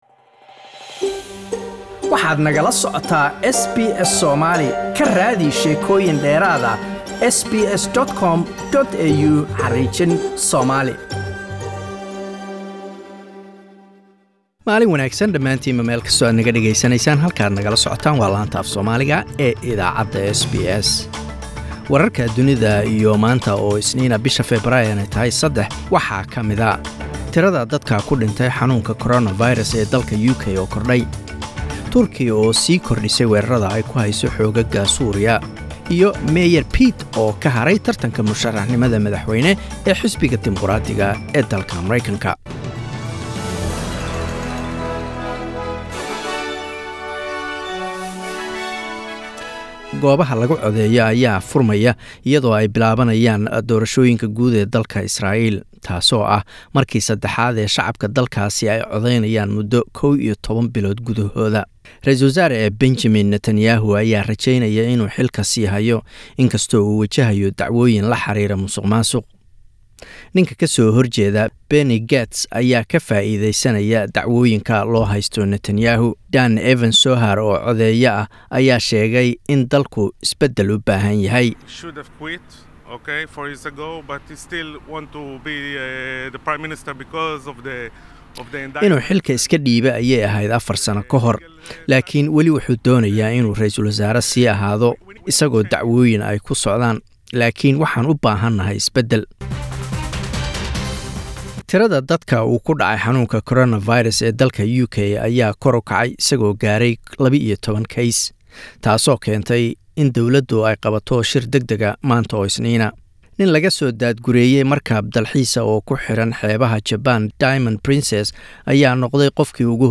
SBS Somali World News Monday 03 Feb
Wararka caalamka ee SBS Somali